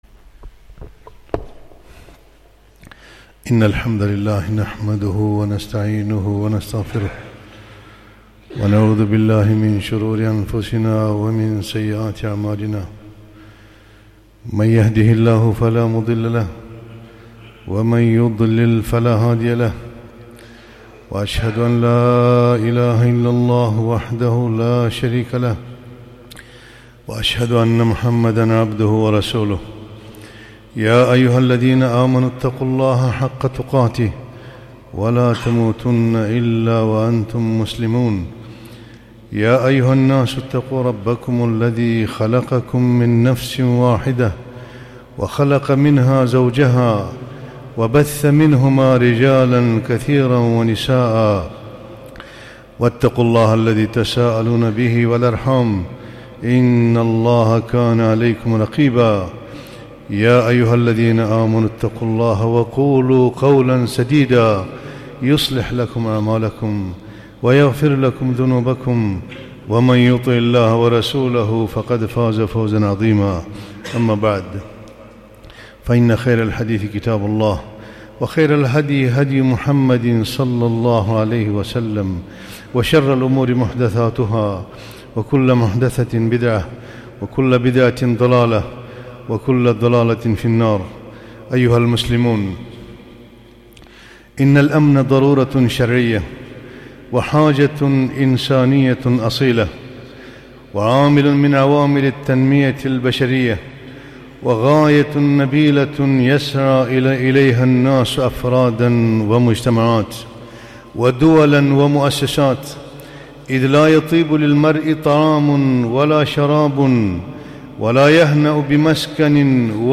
خطبة - ( إن هذه أمتكم أمة واحدة )